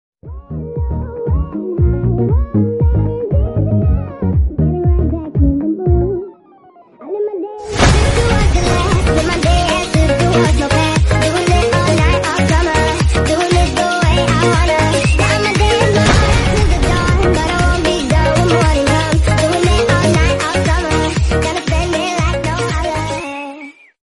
speed up/edit audio